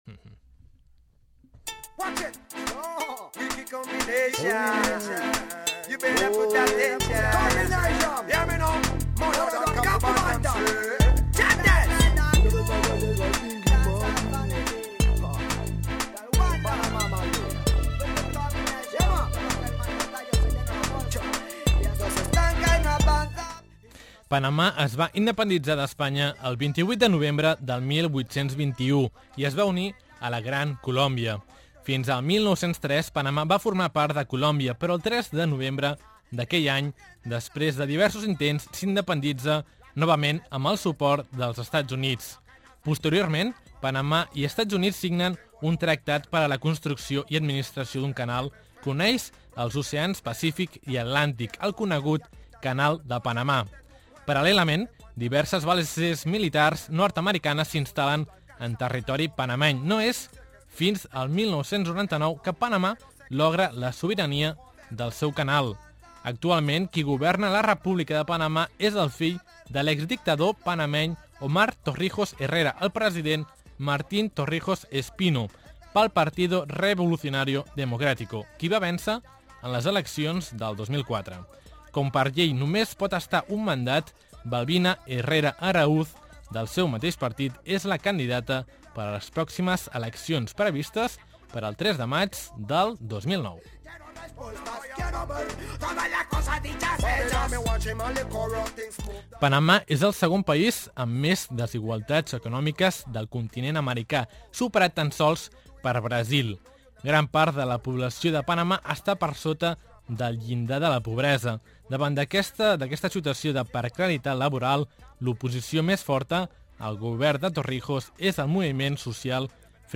Escucha el REPORTAJE sobre Panamá